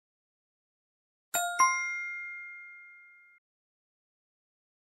دانلود آهنگ جواب درست و غلط 1 از افکت صوتی اشیاء
دانلود صدای جواب درست و غلط 1 از ساعد نیوز با لینک مستقیم و کیفیت بالا
جلوه های صوتی